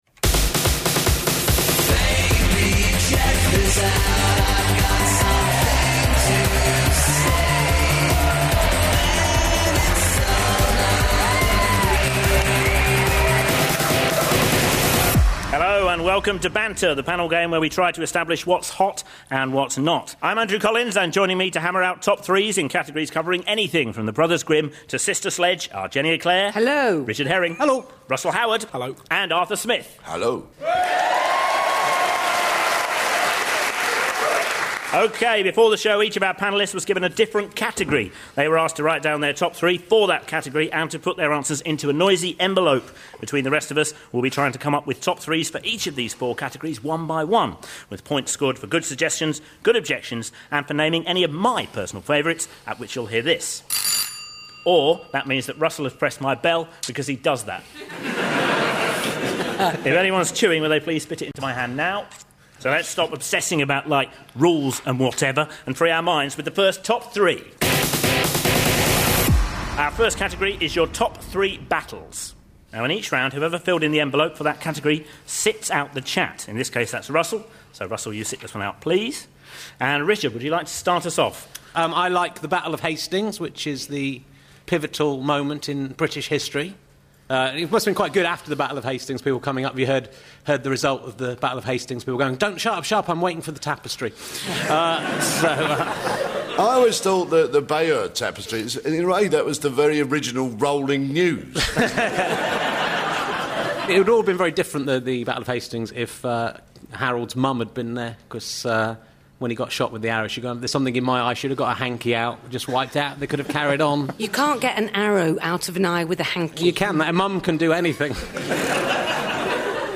Banter is the comedy show in which the nation’s finest comedy talent come up with their definitive ‘Top Threes’. Three highly opinionated regular panelists Richard Herring, Russell Howard and Will Smith, and their host Andrew Collins fight it out, gaining points for entertaining arguments and audience pleasing choices, with categories covering anything from science to cinema, and from playwrights to Playstation.